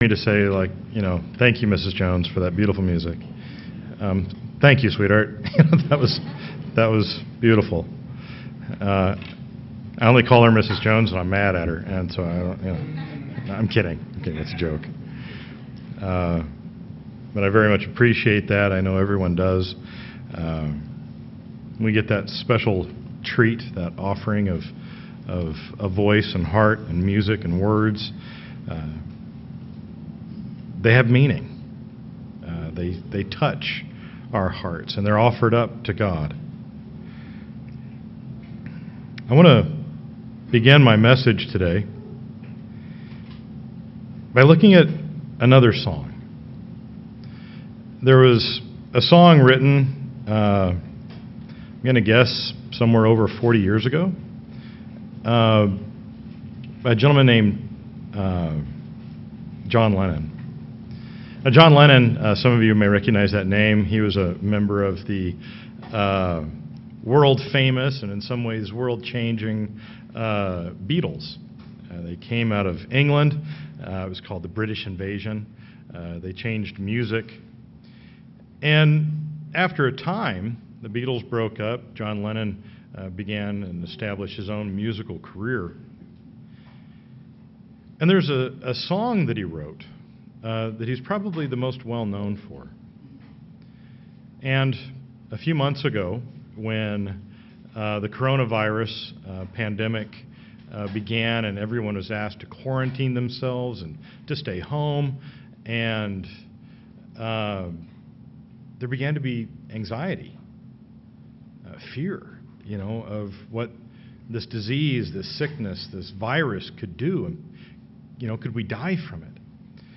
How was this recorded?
Given in Sioux Falls, SD Watertown, SD